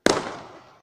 shoot.ogg